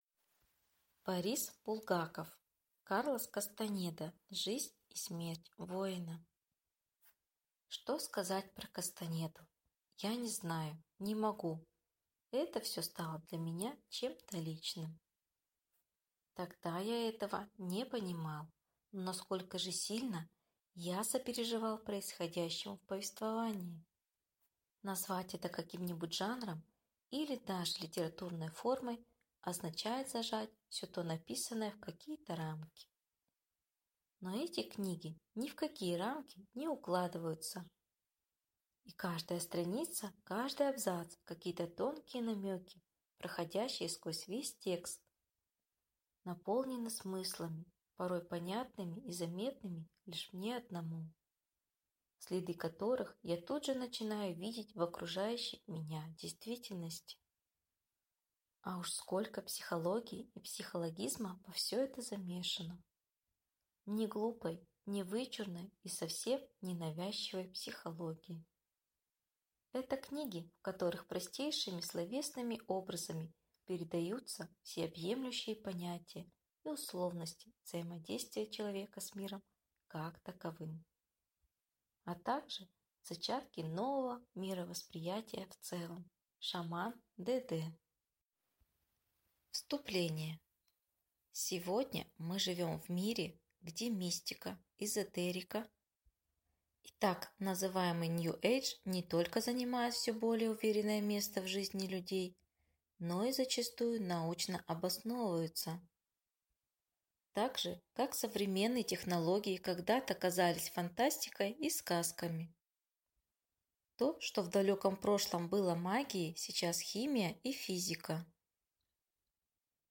Аудиокнига Карлос Кастанеда. Жизнь и смерть Воина | Библиотека аудиокниг